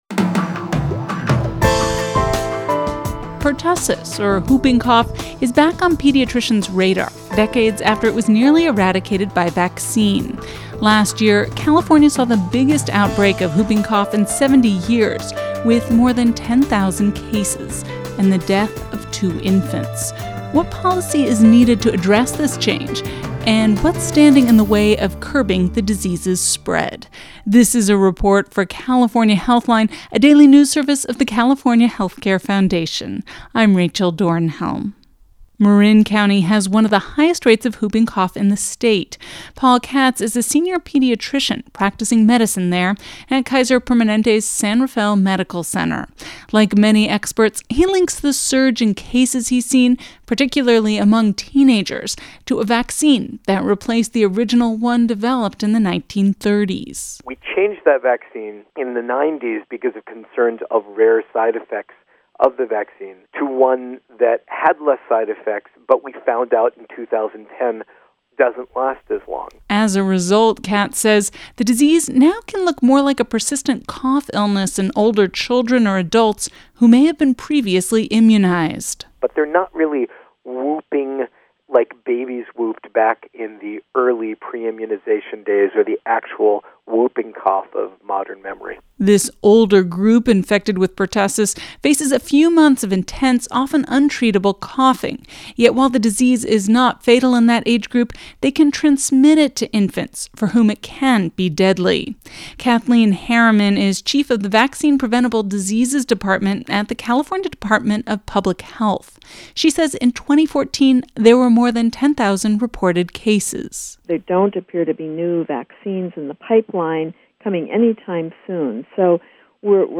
Audio Report Insight Multimedia